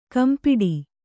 ♪ kampiḍi